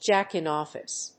アクセントjáck‐in‐òffice